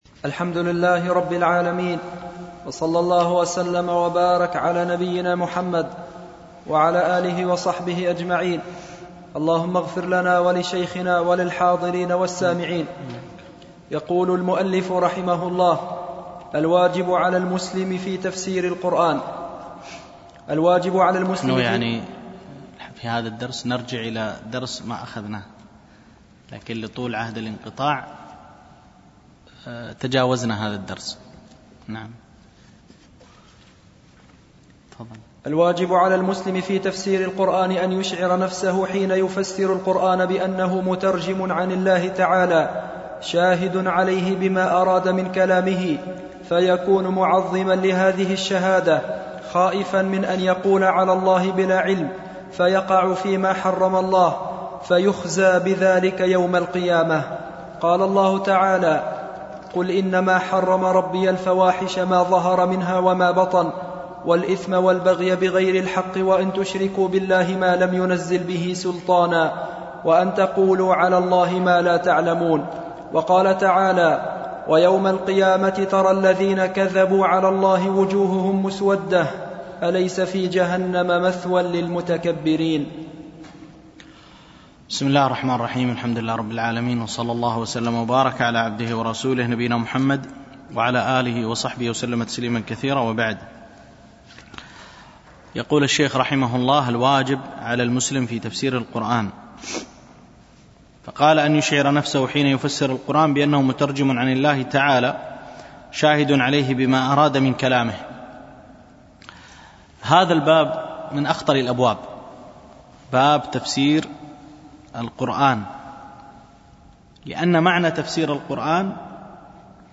دروس مسجد عائشة